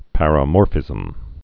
(părə-môrfĭzəm)